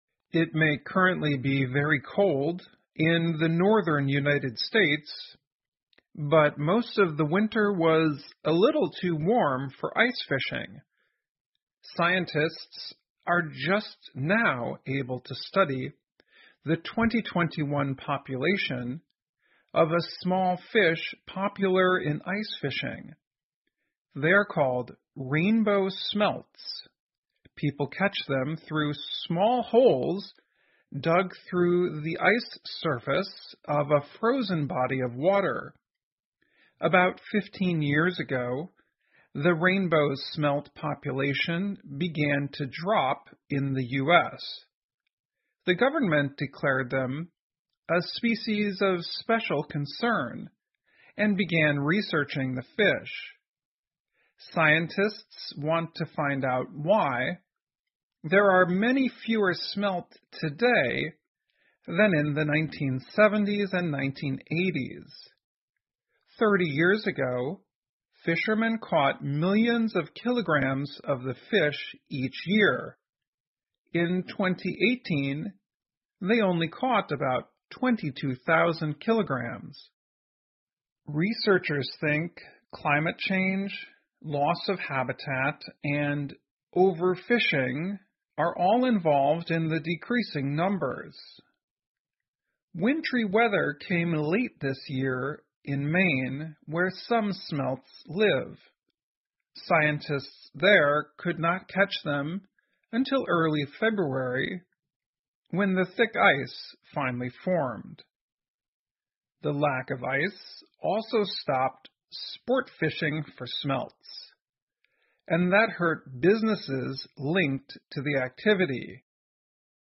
VOA慢速英语--晚到的寒冷缩短了美国的冰上捕鱼时间 听力文件下载—在线英语听力室